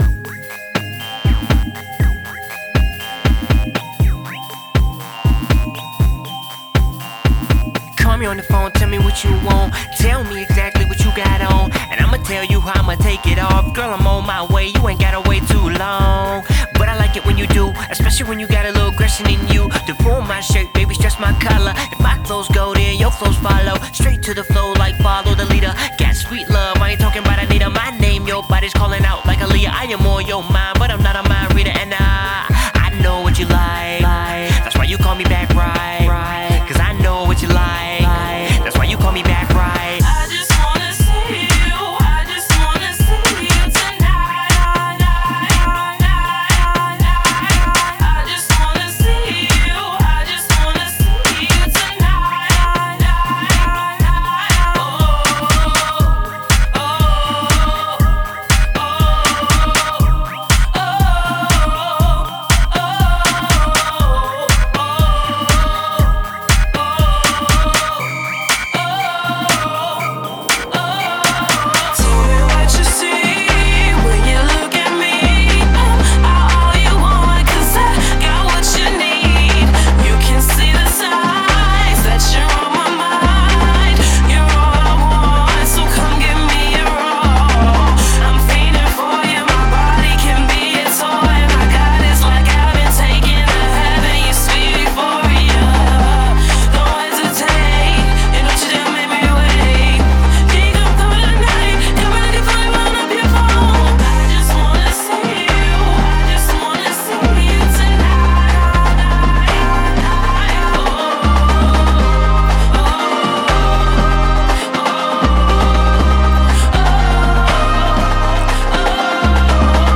BPM120
It's a fun, catchy R&B/Pop track written